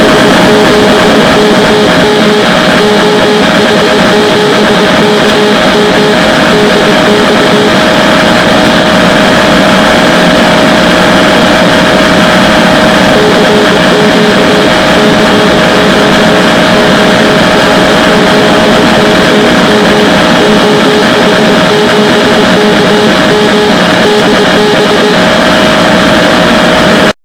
50 MHz DX SOUND FILES
The recordings themselves were made with an old cassette tape recorder located below one of the two outdated Labtec sound-blaster speakers I use as externals.